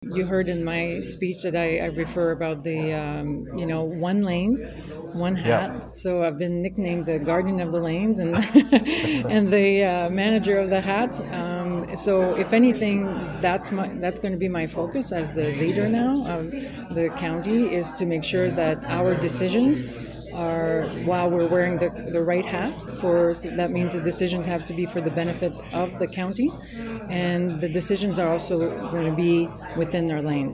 In an interview with myFM afterward, she emphasized the importance of thoughtful leadership on key priorities and promised a year of stability and clarity.